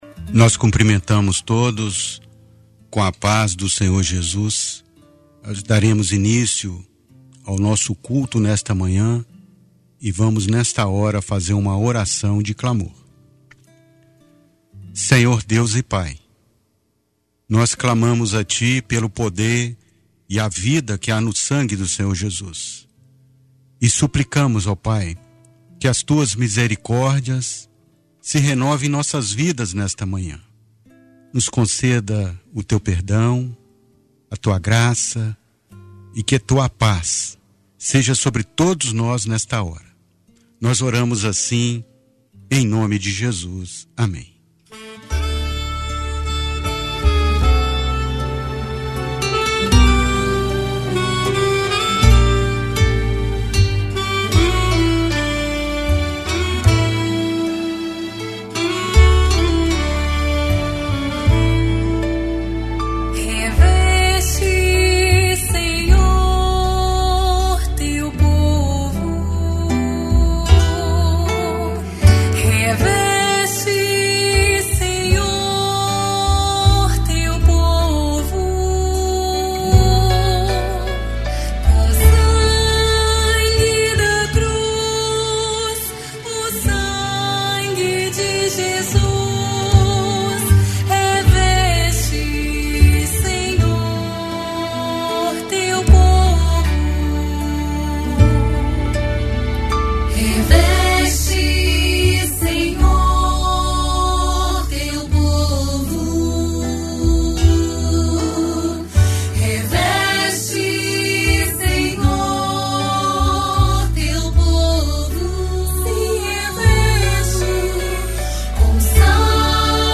Culto de oração transmitido dentro do programa Bom Dia Maanaim